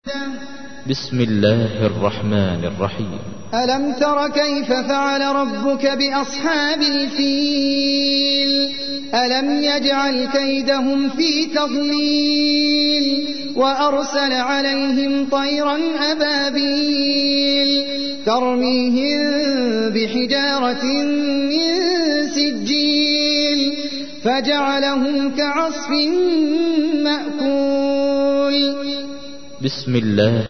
تحميل : 105. سورة الفيل / القارئ احمد العجمي / القرآن الكريم / موقع يا حسين